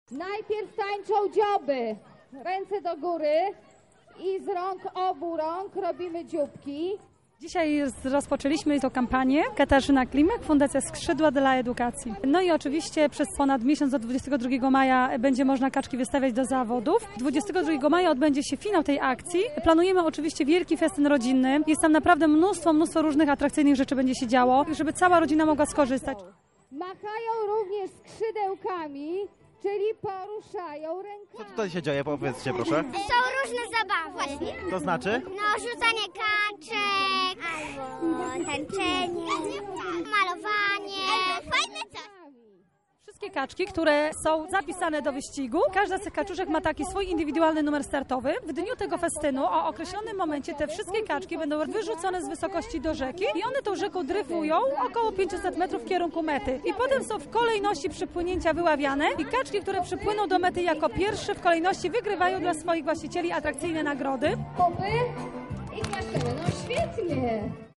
Najmłodsi mieszkańcy Lublina oraz przedstawiciele Fundacji Skrzydła dla Edukacji przygotowali specjalny happening. Zaczął się od wspólnego zatańczenia popularnych Kaczuch.